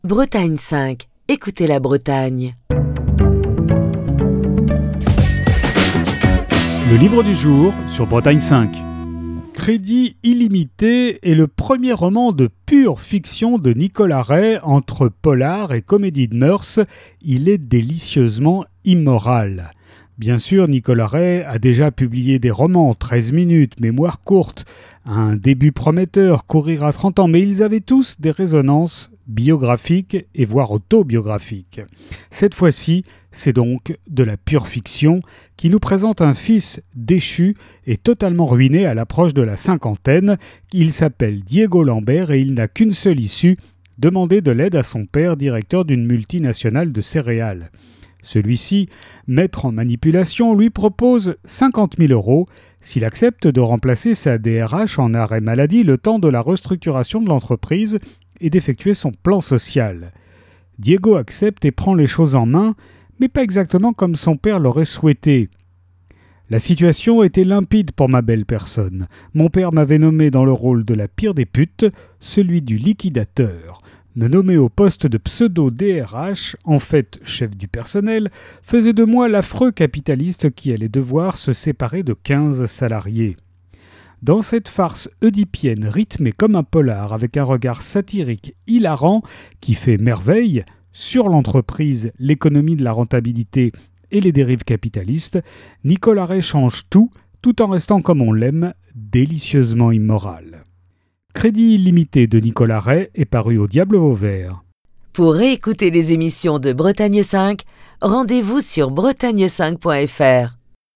Chronique du 13 septembre 2022.